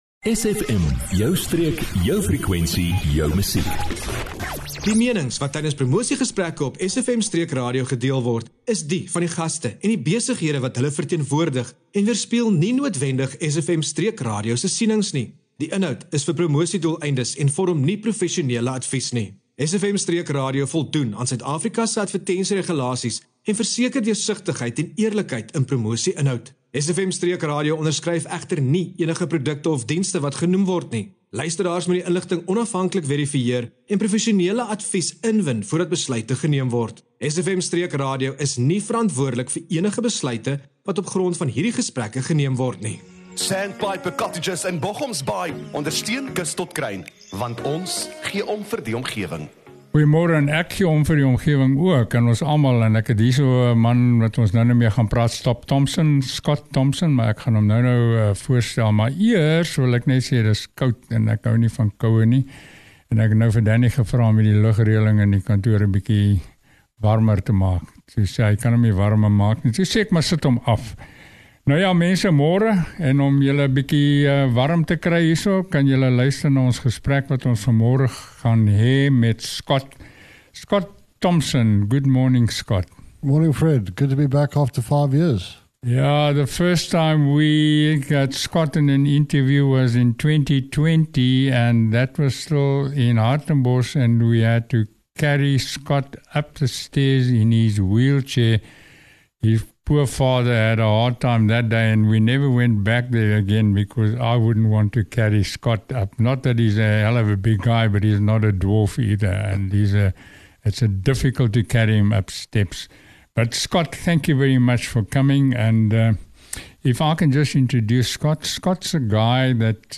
🌿 Today on Kus tot Kruin | 11h30 on SFM Streek RADIO 🎙 Join us for a powerful and inspiring conversation